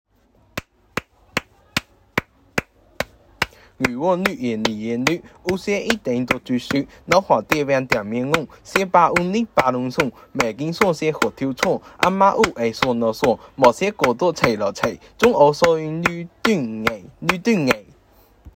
好吃歌 RAP | 福州话资源网